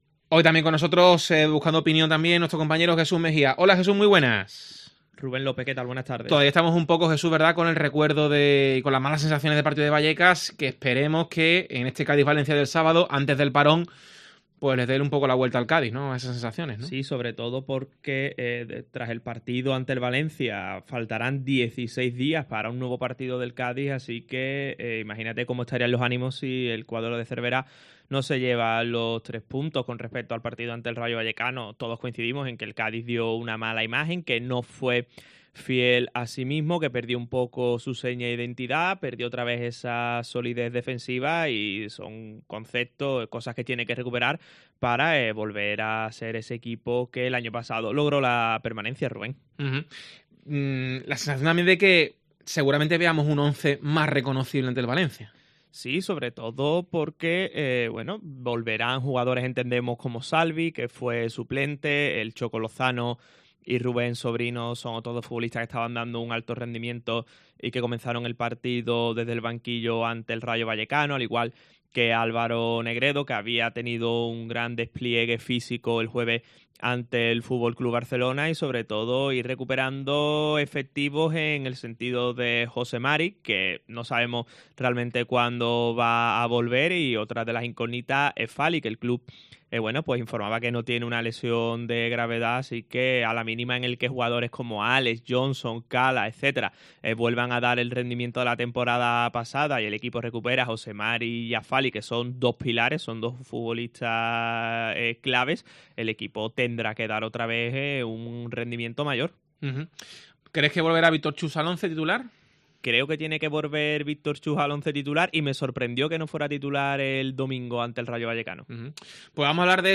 Víctor Chust, jugador del Cádiz, en Deportes COPE Cádiz